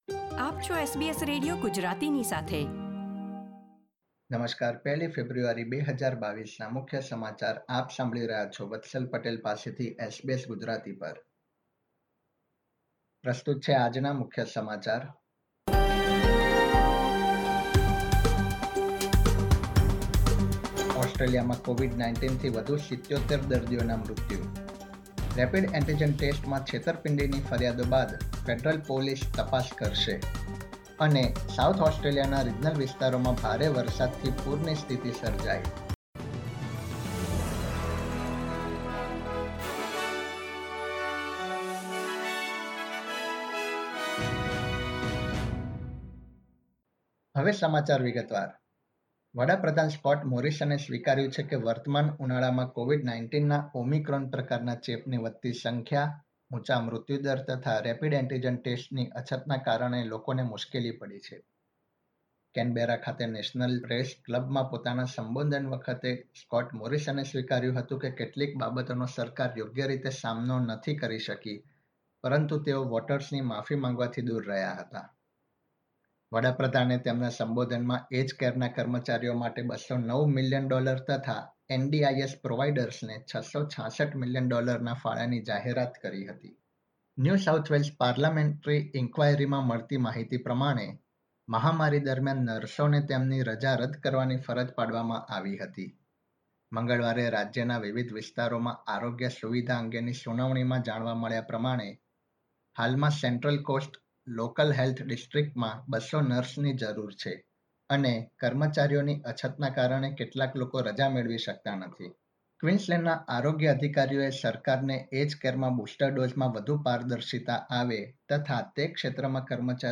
SBS Gujarati News Bulletin 1 February 2022